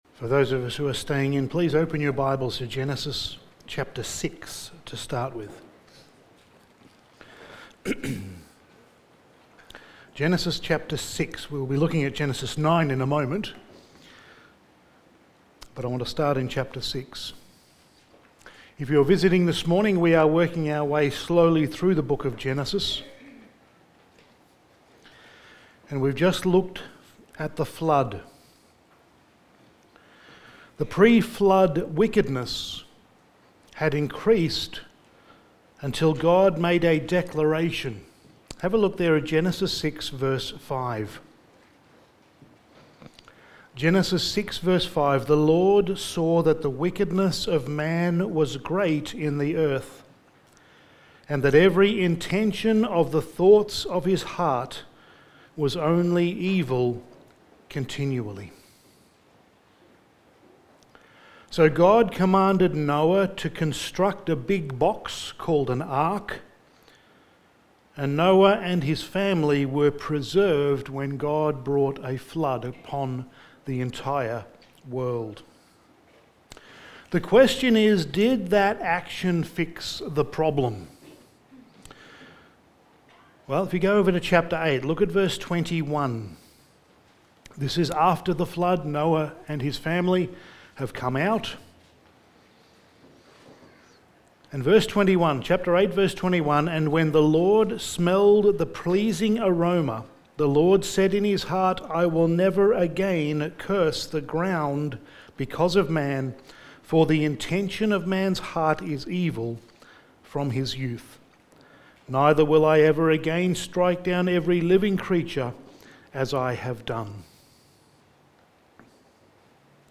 Sermon
Genesis 9:17-29 Service Type: Sunday Morning Sermon 14 « A New Beginning Toledot #4